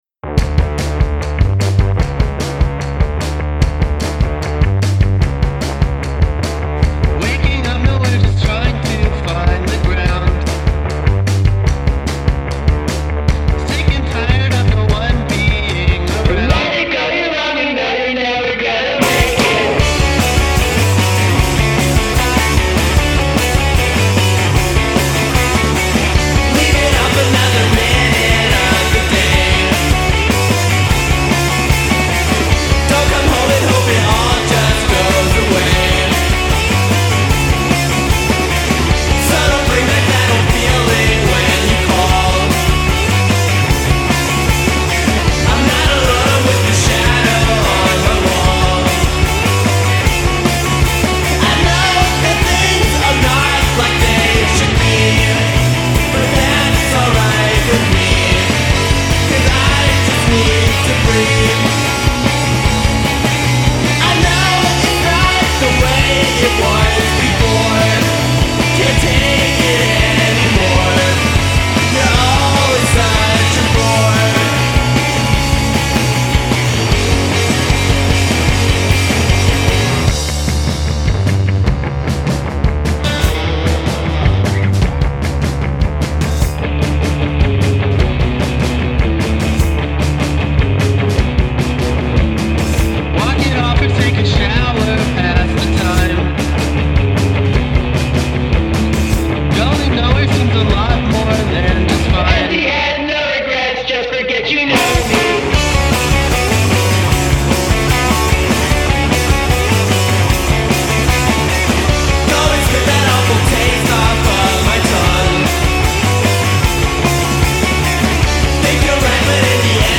il suono si è fatto più pop e meno lo-fi